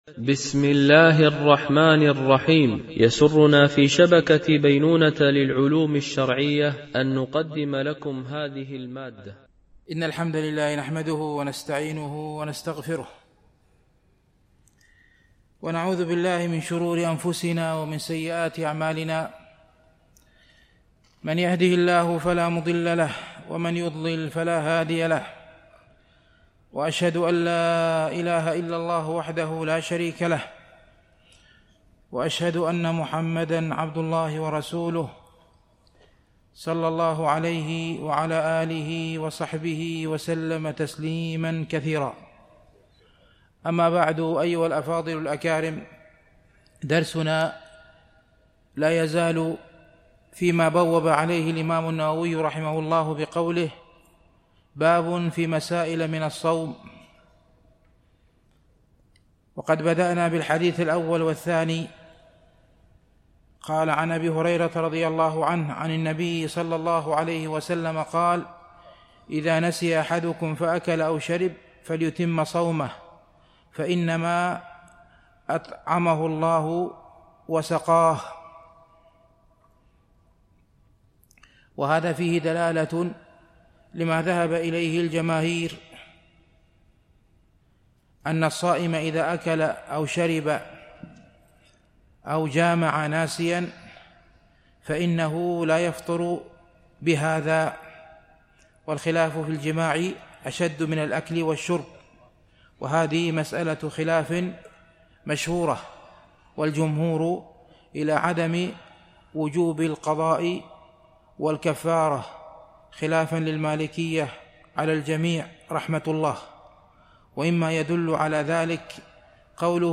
التنسيق: MP3 Mono 44kHz 64Kbps (CBR)